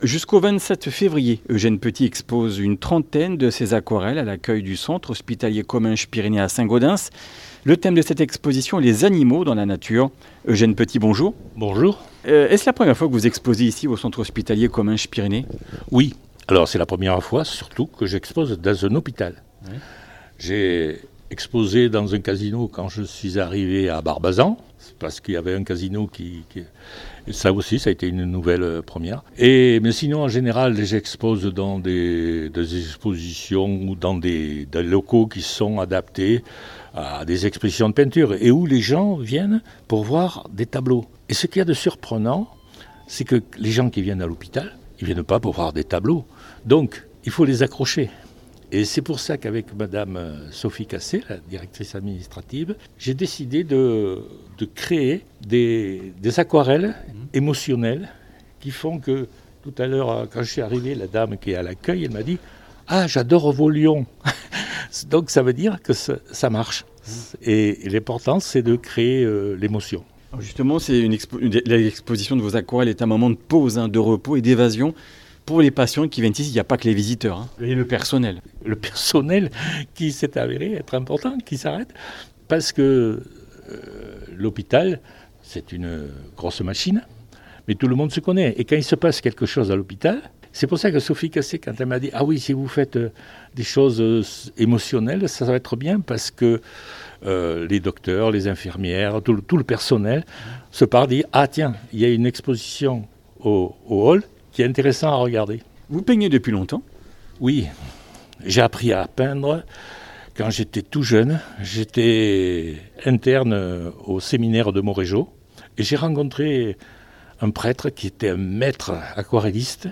Comminges Interviews du 21 janv.